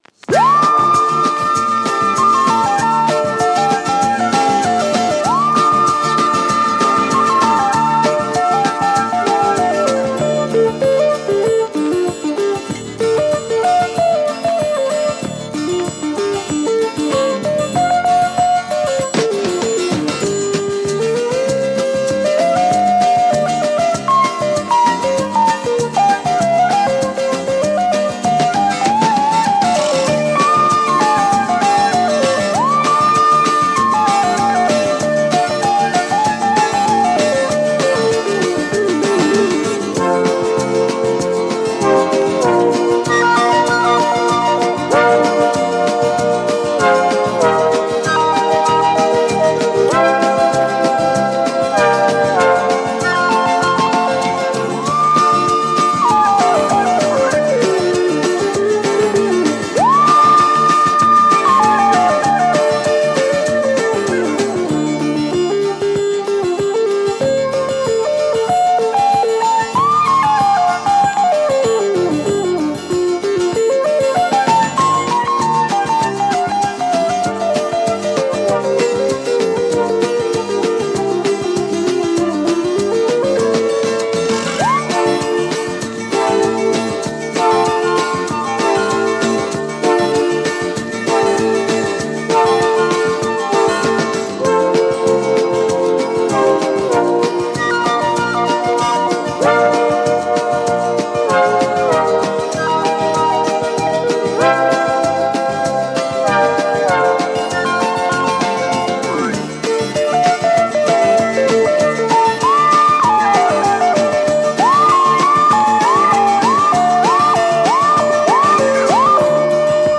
French Library groove
fantastic b-boy lib 2Siders!